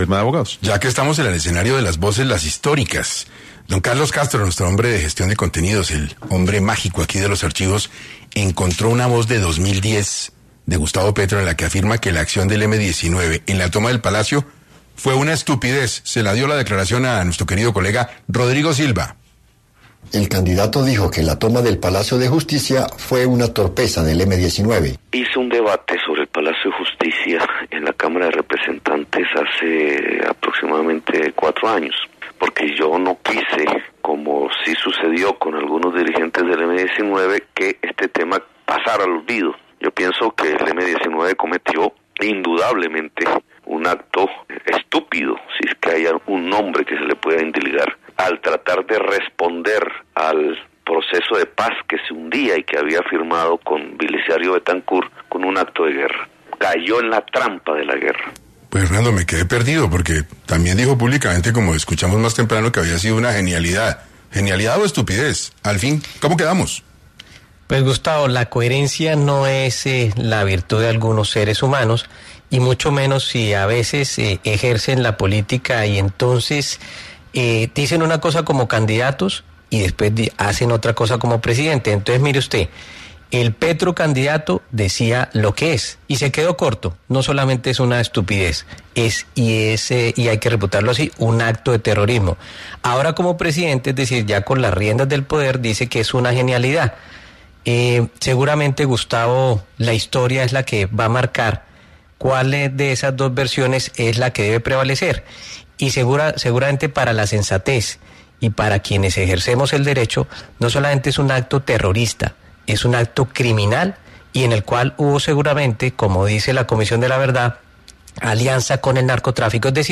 Esta declaración se produjo en el contexto de un debate sobre el Palacio de Justicia en la Cámara de Representantes, que Petro impulsó para evitar que el tema cayera en el olvido.